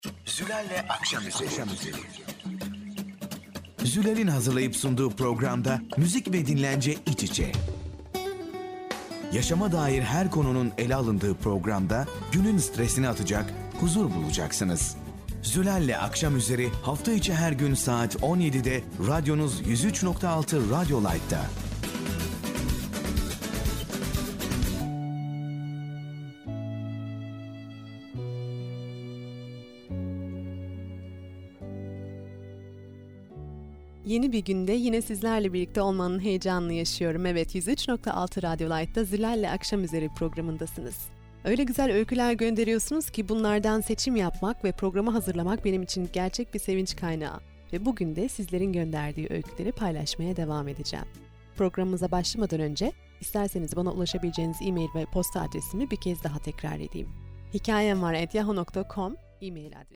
Hörbeispiel türkische Jugendsendung.